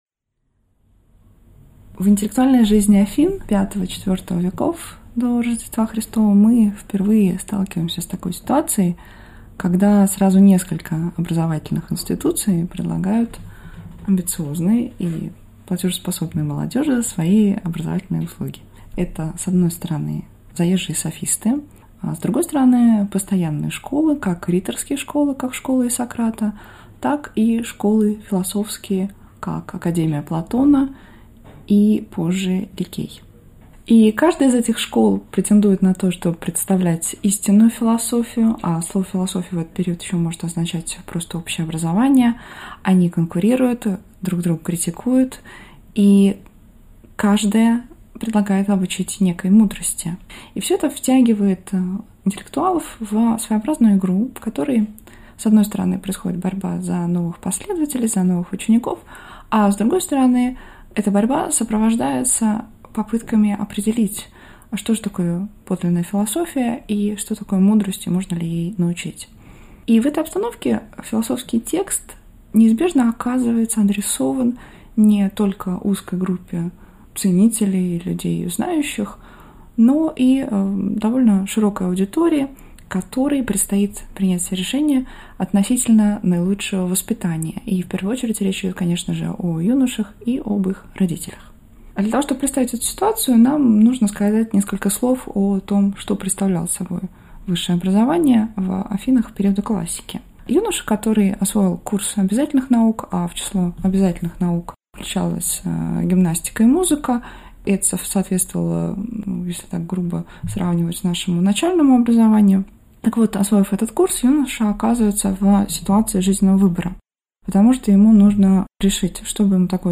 Аудиокнига Школа vs. площадь | Библиотека аудиокниг